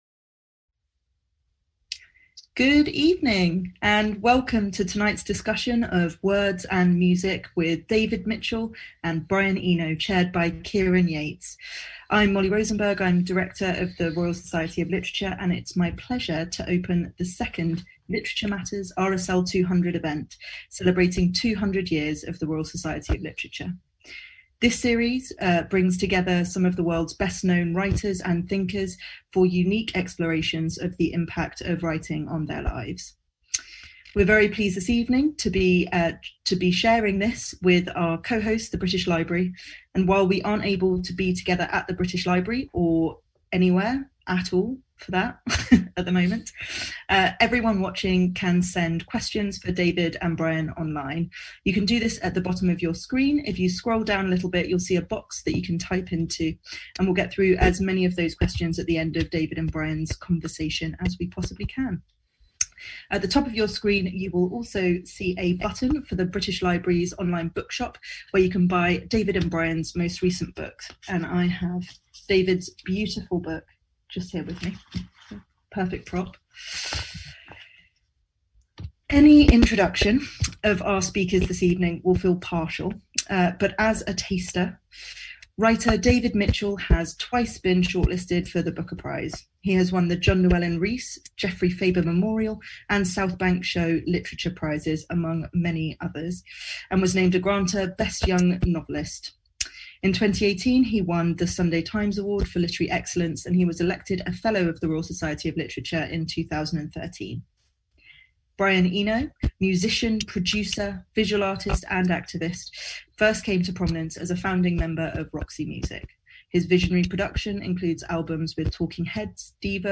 And now again, a new conversation, in times where many of us have a lot of extra time.